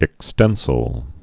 (ĭk-stĕnsĭl)